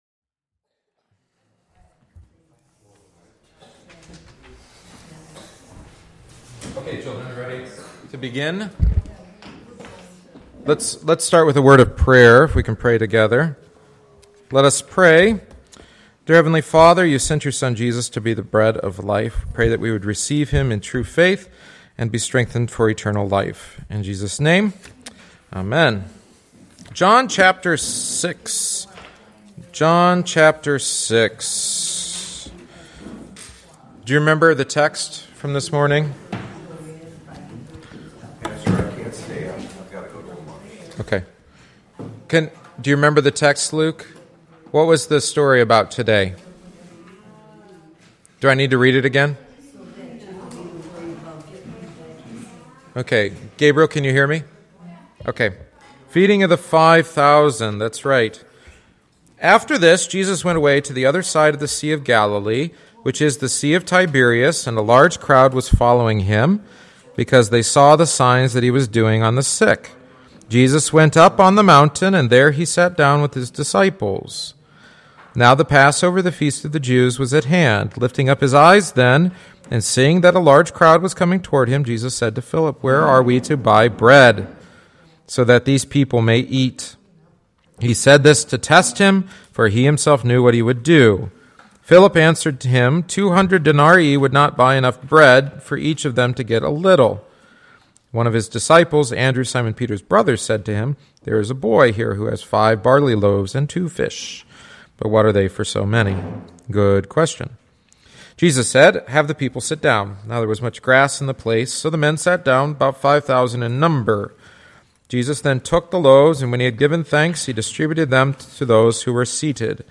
Catechesis on the Feeding of the 5000